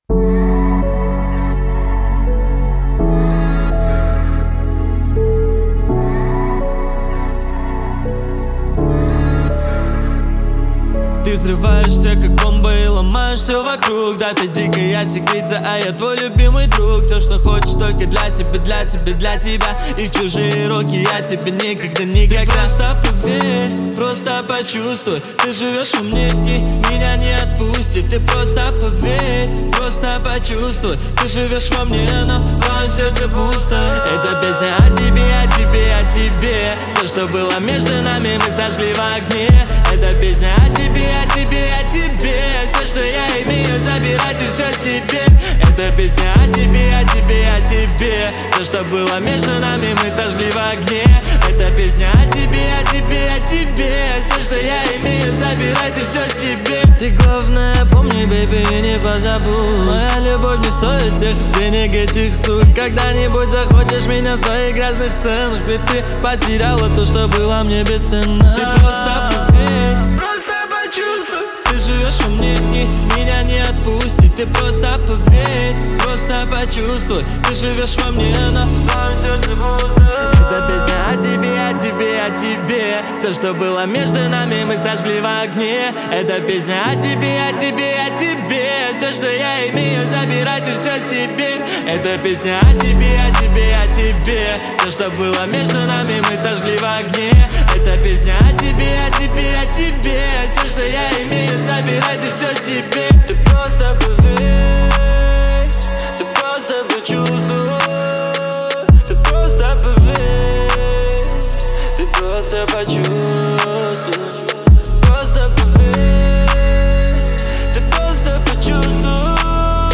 это эмоциональный трек в жанре поп